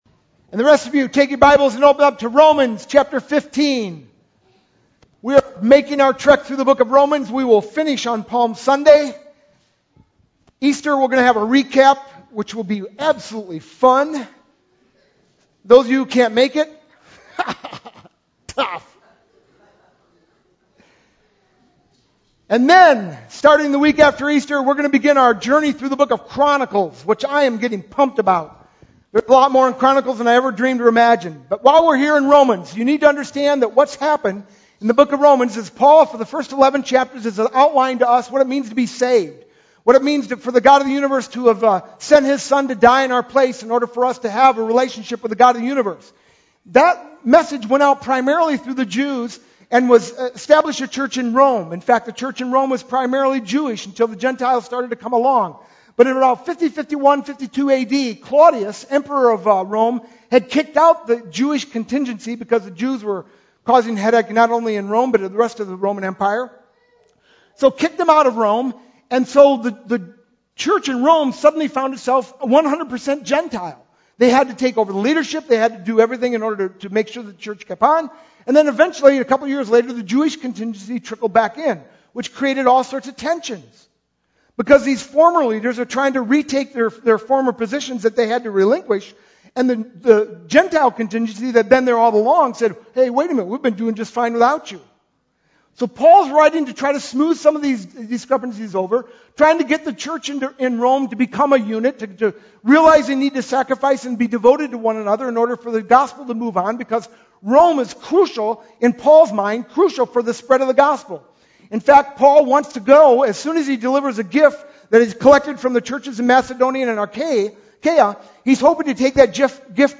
sermon-3-11-12.mp3